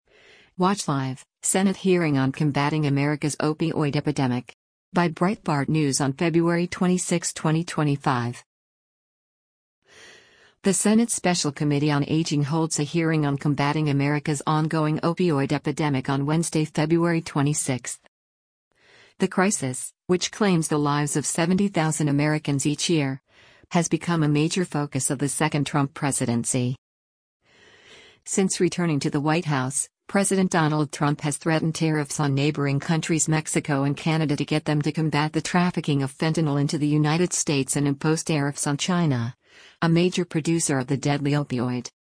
The Senate Special Committee on Aging holds a hearing on combatting America’s ongoing opioid epidemic on Wednesday, February 26.